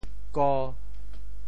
挝（撾） 部首拼音 部首 扌 总笔划 14 部外笔划 11 普通话 zhuā wō 潮州发音 潮州 zua1 文 go5 文 中文解释 “老挝”:亚洲一国名 wo 挝 <动> zhua 敲打,击 [beat] 令鼓吏挝鼓。